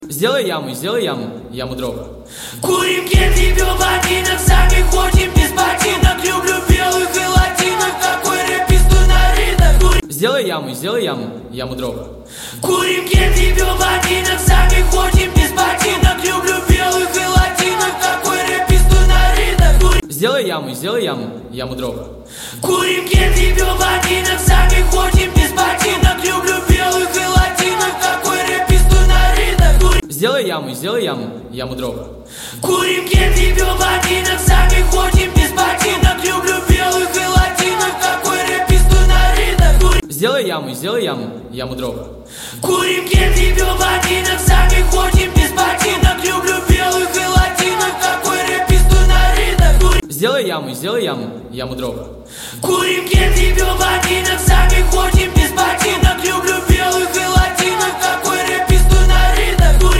Качество: 128 kbps, stereo